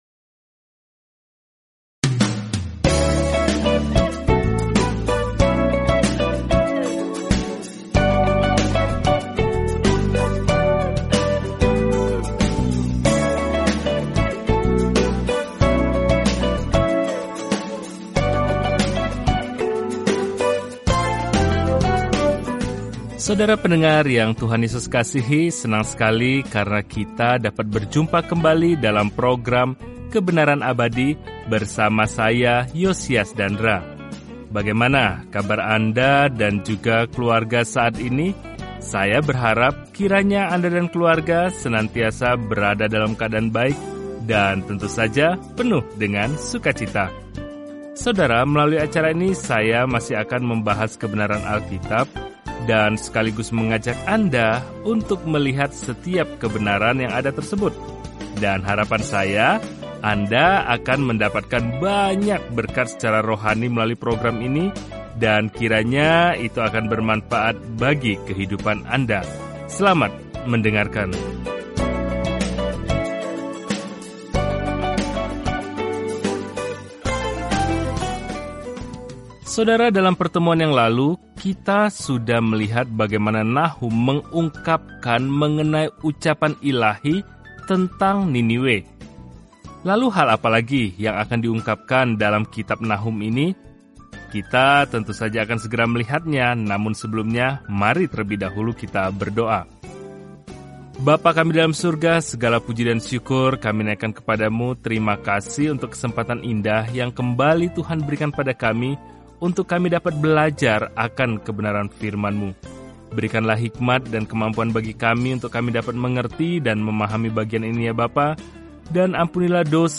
Firman Tuhan, Alkitab Nahum 1:2-3 Hari 1 Mulai Rencana ini Hari 3 Tentang Rencana ini Nahum, yang namanya berarti penghiburan, membawa pesan penghakiman kepada musuh-musuh Tuhan dan membawa keadilan dan harapan bagi Israel. Perjalanan sehari-hari melalui Nahum sambil mendengarkan studi audio dan membaca ayat-ayat tertentu dari firman Tuhan.